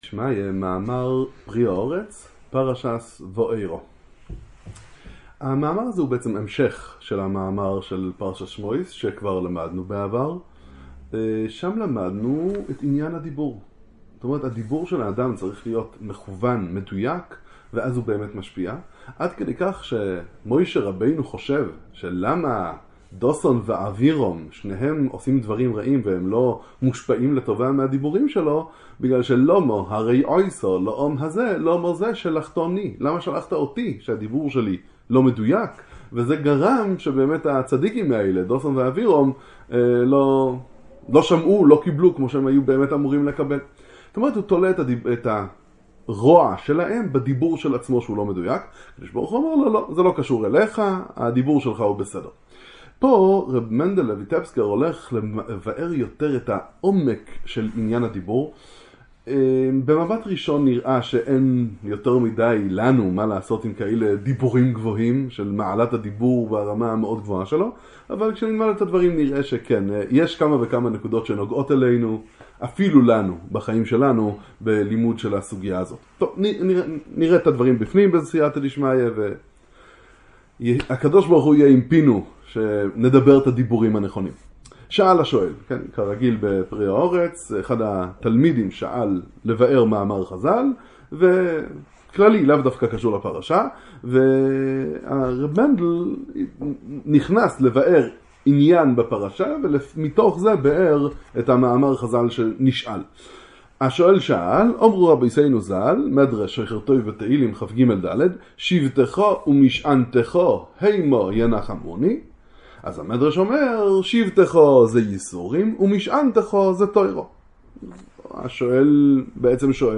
שיעור בספר הקדוש פרי הארץ לרבי מנחם מנל מויטפסק זי"ע